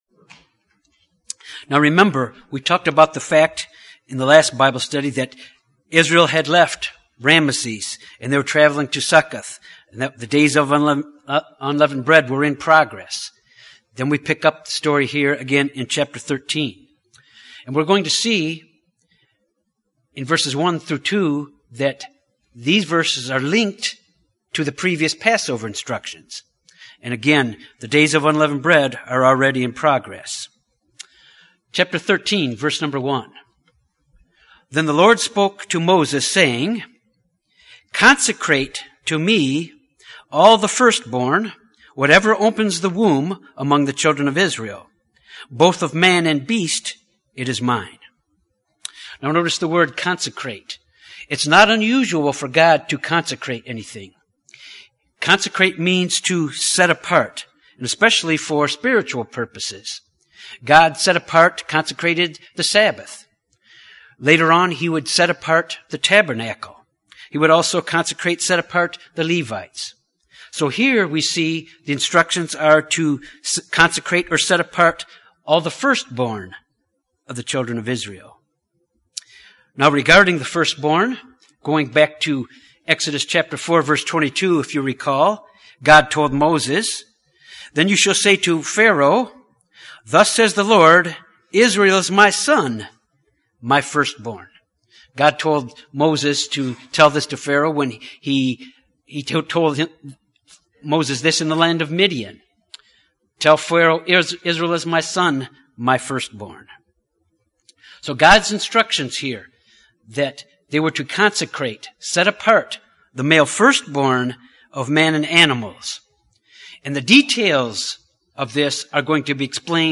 This bible study deals with keeping the Feast of Unleavened Bread and the law of the first born. Also covered is the journey of the Israelites to the Red Sea and their crossing of it.
Given in Jonesboro, AR